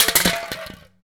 PRC BOWL D07.wav